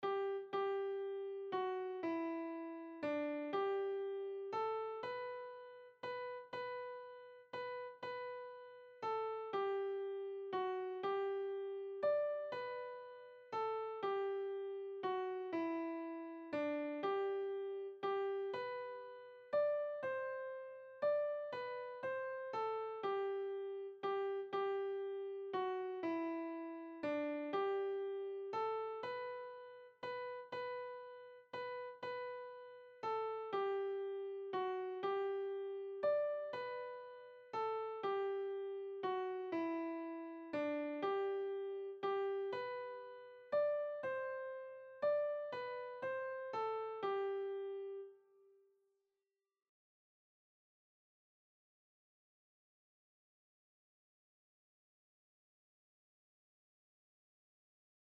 RG 60: Nun lob, mein Seel, den Herren - Kanon